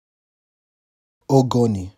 Ogoni (pronounced OH-goh-KNEE), one of the oldest ethnic groups in the resource-rich Niger Delta region.